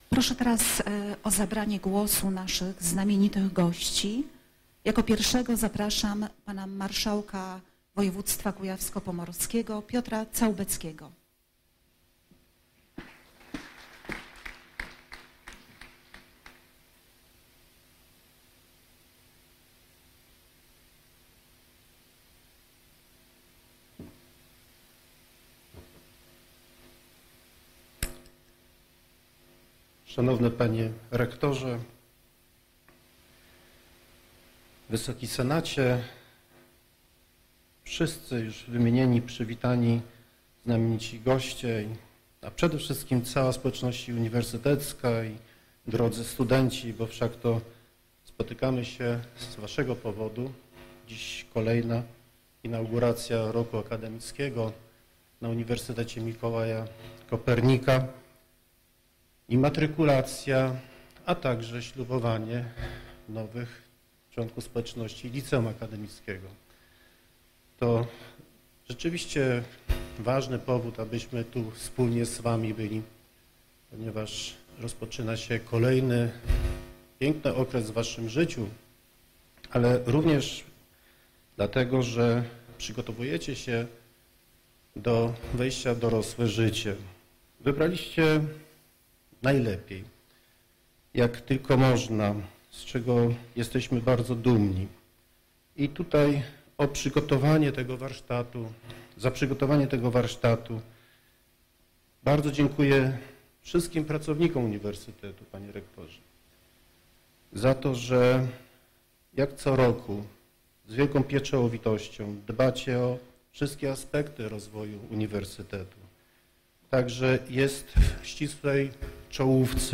Przemówienie marszałka Piotra Całbeckiego (mp3, dzięki uprzejmości Radia Sfera)